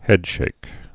(hĕdshāk)